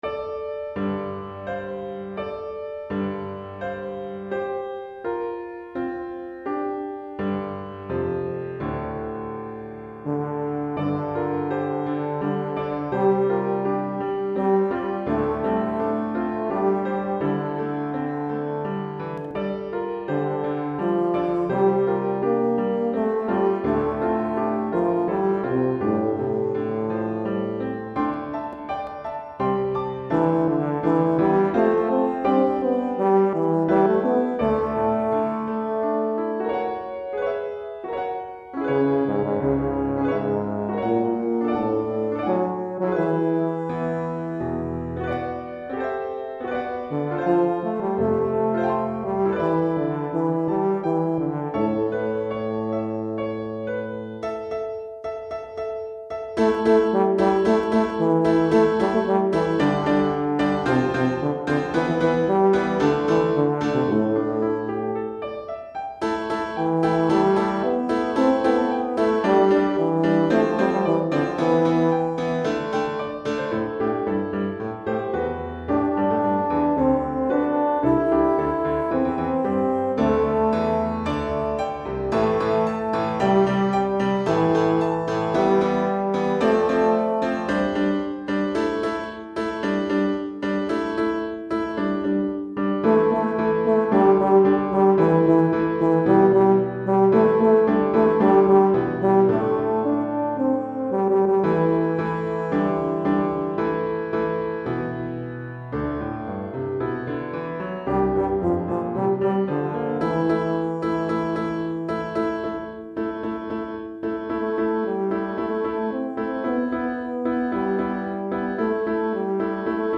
Répertoire pour Tuba, euphonium ou saxhorn